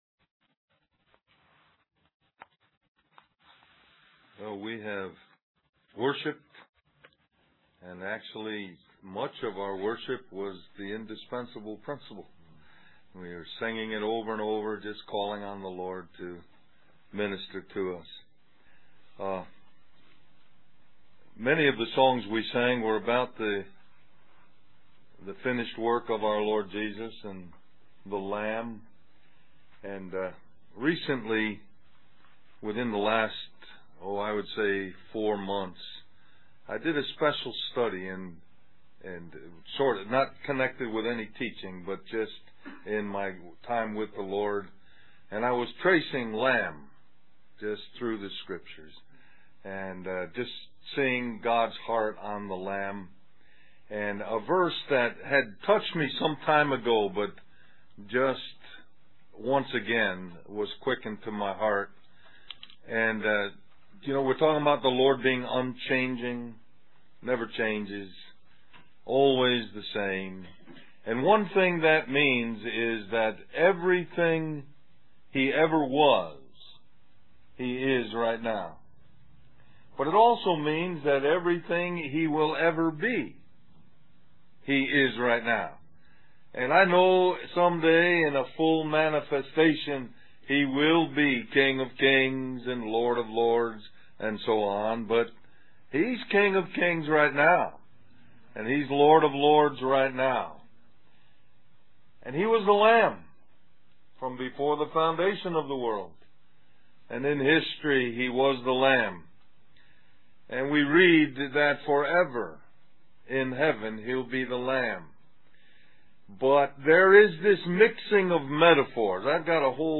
Del-Mar-Va Men's Retreat